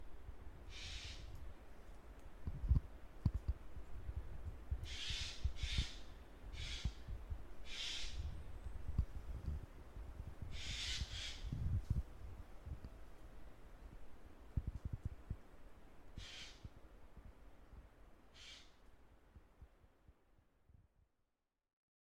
• ほか動物の声（屋久島にて収録）
カケス